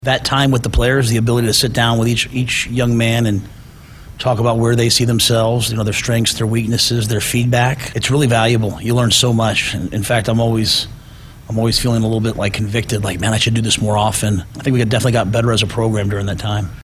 Head Coach for Nebraska Football Matt Rhule spoke on Sports Nightly on Monday night saying he was happy to finally have some time to meet and greet his players during the off-time after the spring game…